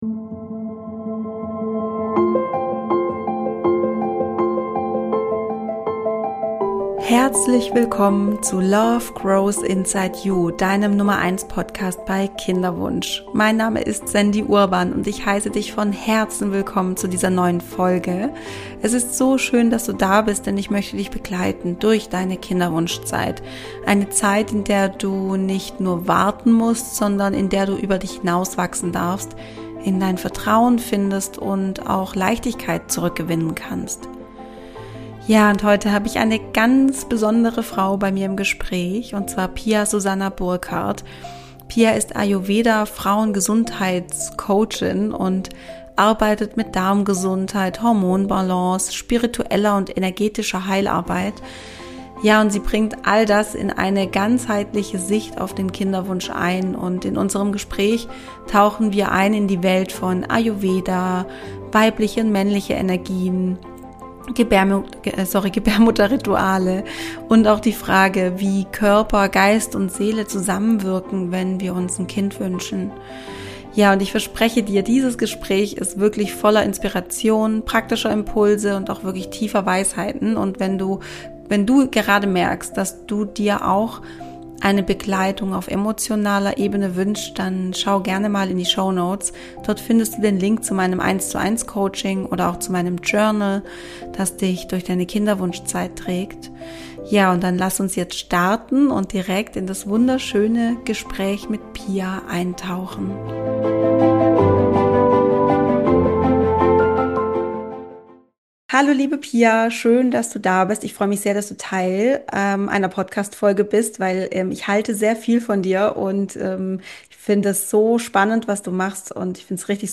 Heute erwartet dich ein inspirierendes Gespräch